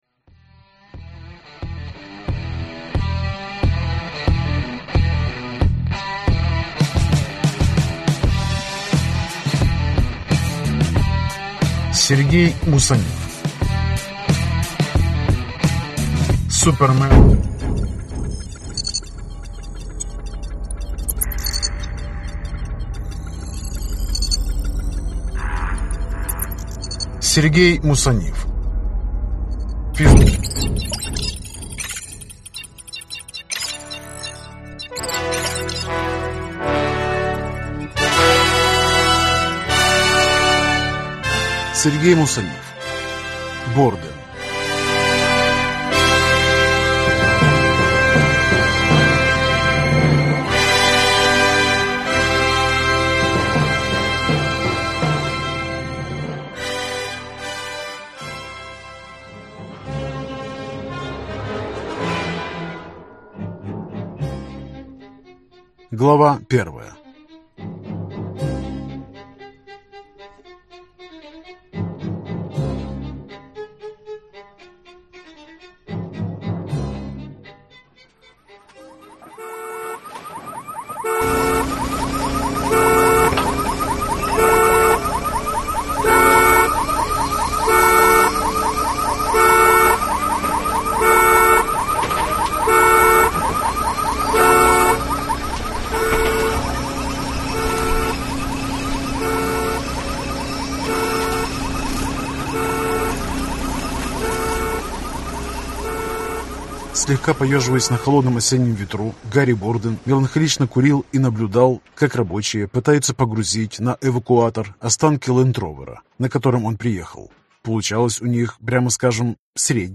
Аудиокнига Борден. Книга 7 | Библиотека аудиокниг
Прослушать и бесплатно скачать фрагмент аудиокниги